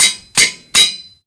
bow.ogg